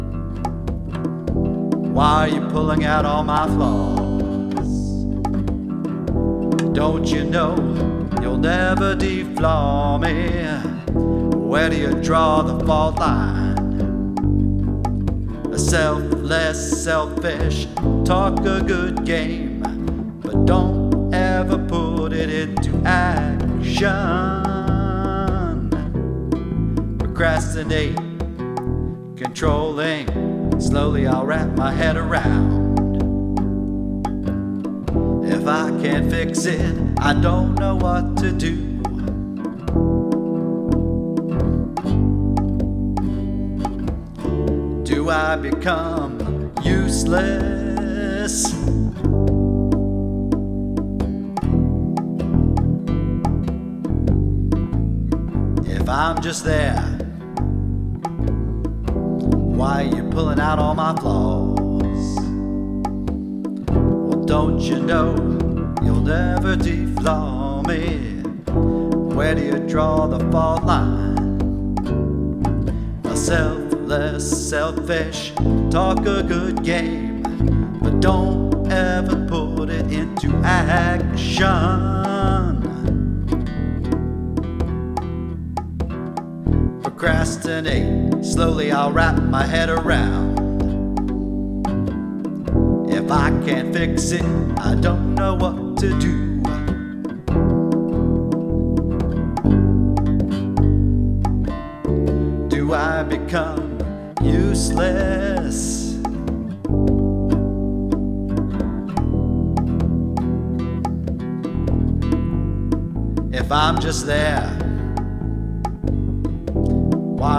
I used you compression tips and I think I can hear a slight difference, not sure.